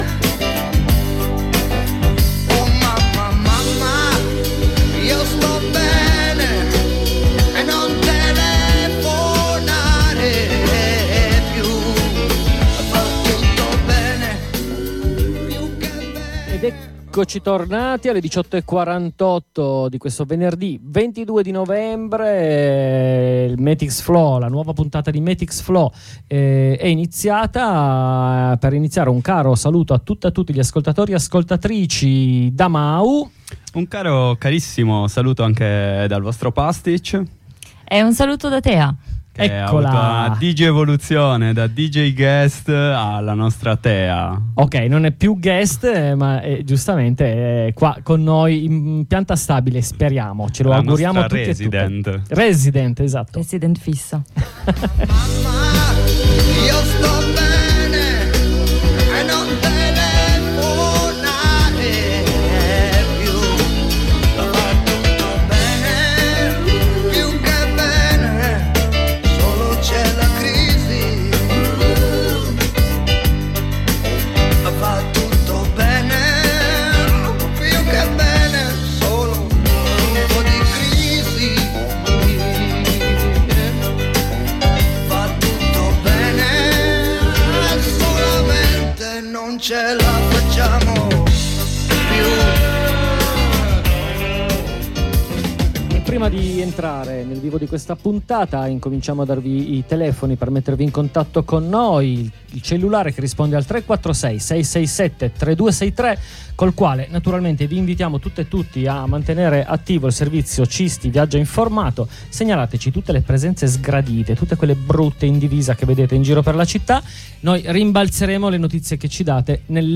Tema principale di questa puntata è il confine Est della fortezza Europa. Ne abbiamo parlato con due compagni di Rotte Balcaniche che ci hanno raccontato cosa succede sulla frontiera tra Turchia e Bulgaria. Ma prima la consueta rassegna stampa della settimana a tema migranti.